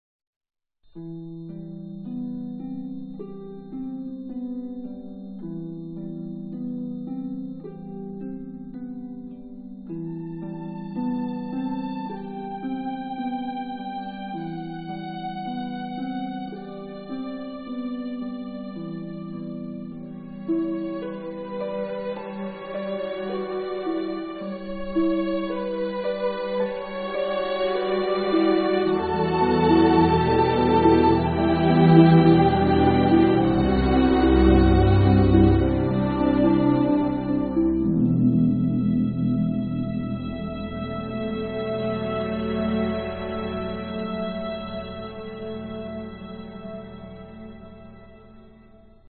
One stylish soundtrack.